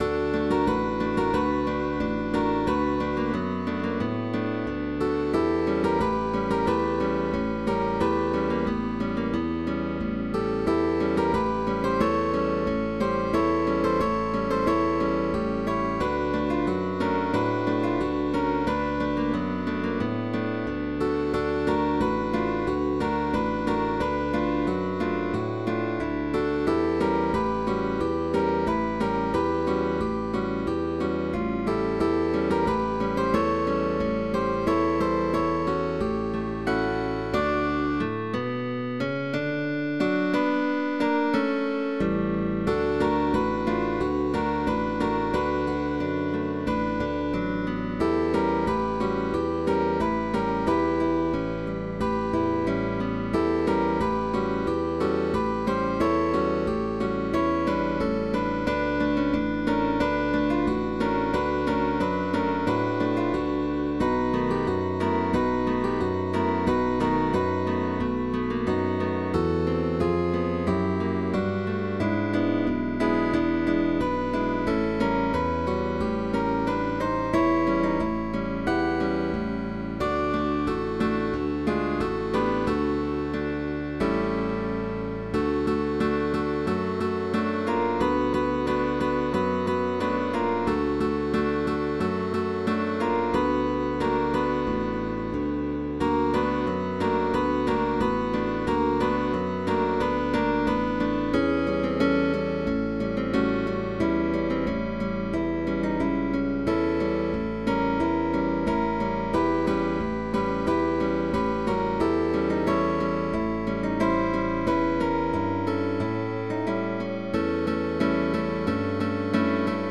Guitar orchestra